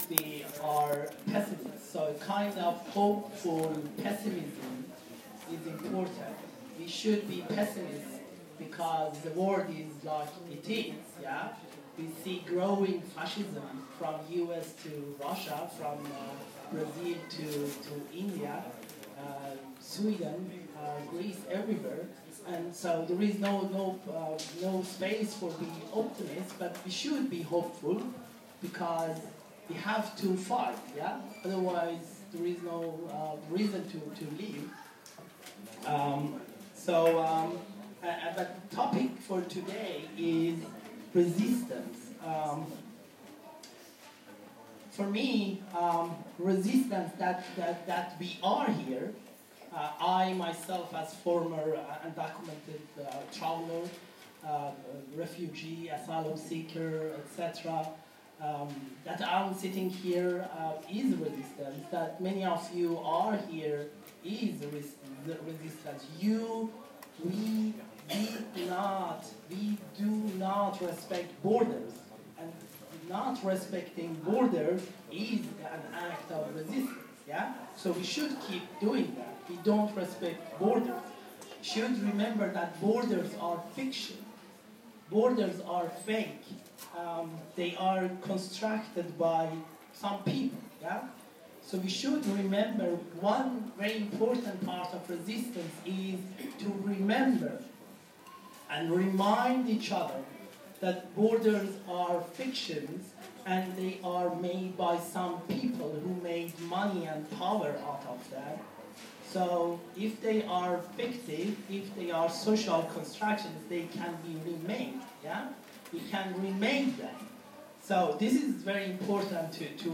Arbitrary Distinction: Performance, Palazzo Bembo, Venezia 2019